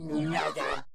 Звук призыва монстра Cybop